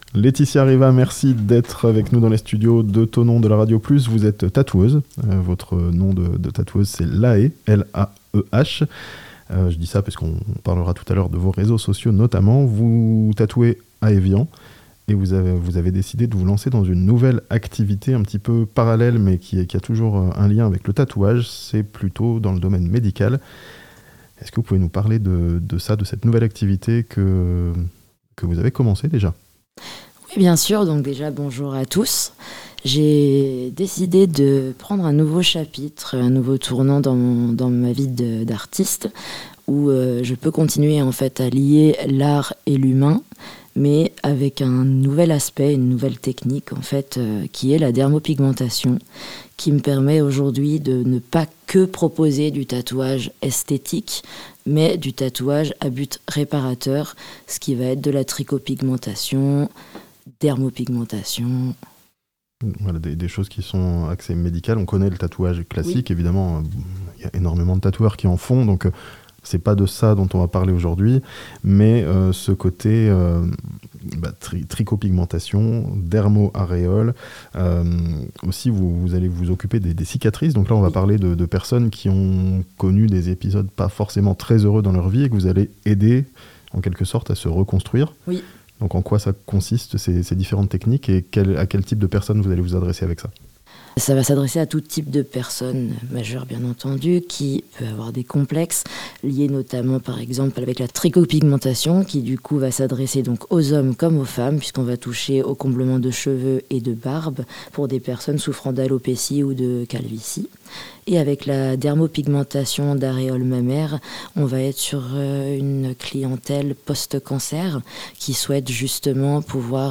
A Evian, le tatouage pour réparer les corps abimés (interview)